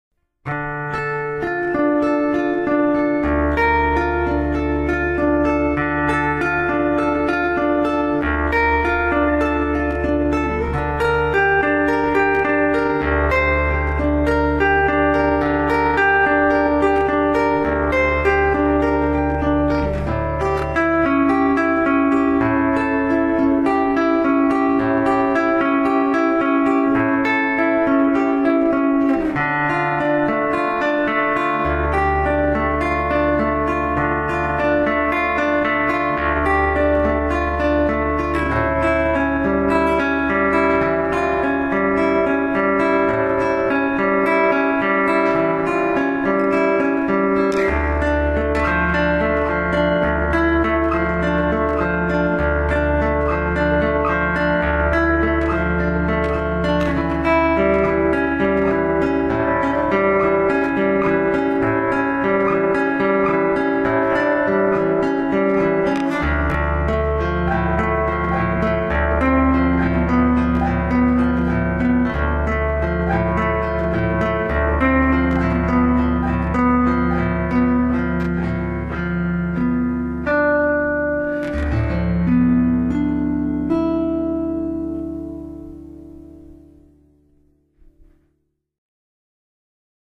Image Vos compositions, créations personnelles.
Alors, comme vous avez été très sages et bien gentils tout plein, voici la version électro-acoustique (ah bah oui, j'ma racheté une pile qui va bien dedans, parce que l'autre, ben elle était aussi naze que la corne sur le bout de mes pauvres petits doigts tout meurtris)